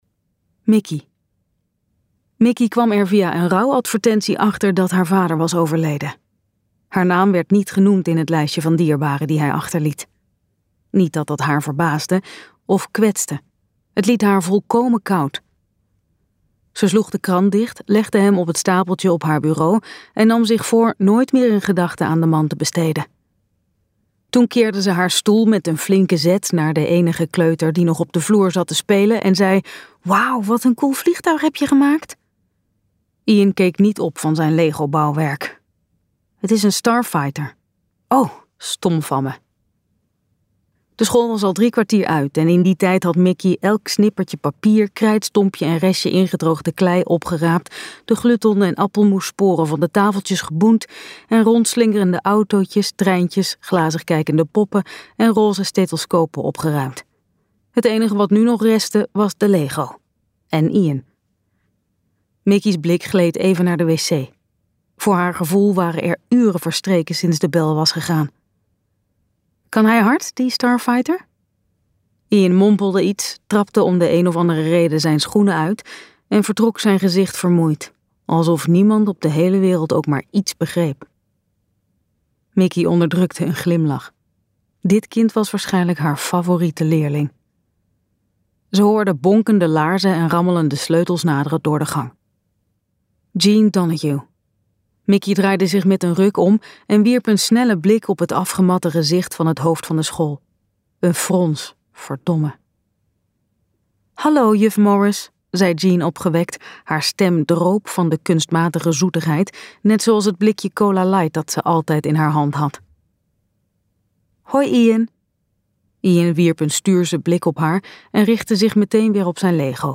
Ambo|Anthos uitgevers - Favoriete dochter luisterboek